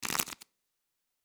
pgs/Assets/Audio/Fantasy Interface Sounds/Cards Shuffle 1_05.wav at master
Cards Shuffle 1_05.wav